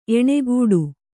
♪ eṇegūḍu